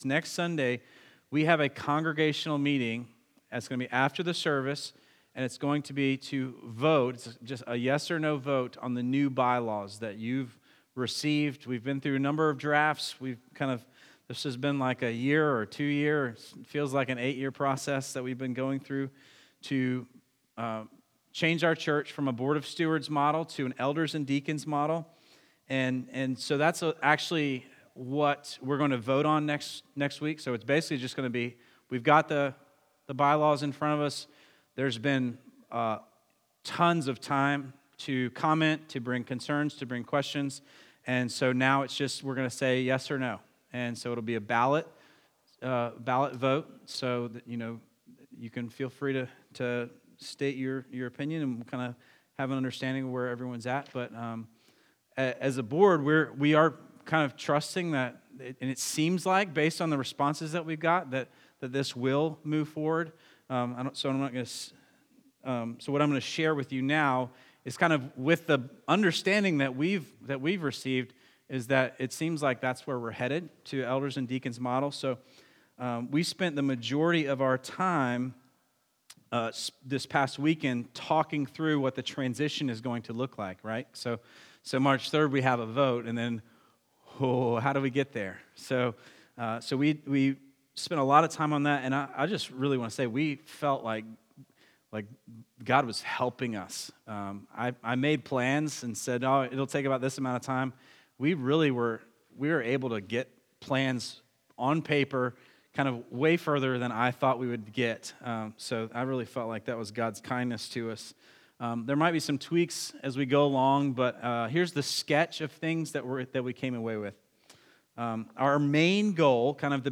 Before I get into the sermon for today, I want to give you a recap of our board retreat.